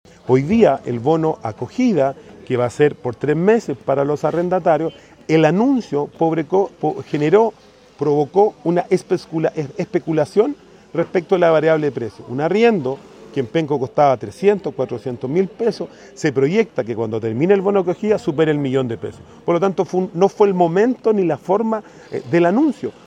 El alcalde de Penco, Rodrigo Vera, también advirtió de esta situación y agregó que en este caso los beneficios del Gobierno están provocando esta especulación.